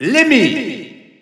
Announcer pronouncing Lemmy in French.
Lemmy_French_Announcer_SSBU.wav